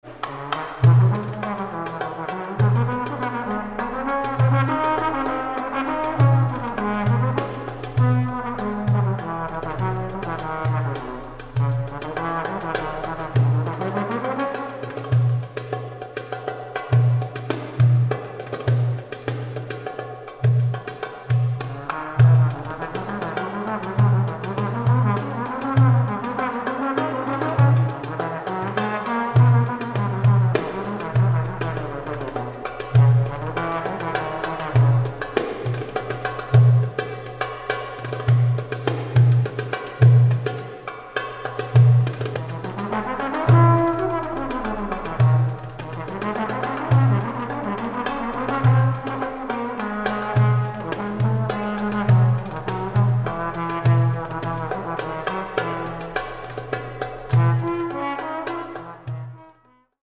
Historical Drums
Recording: Gut Hohen Luckow, 2024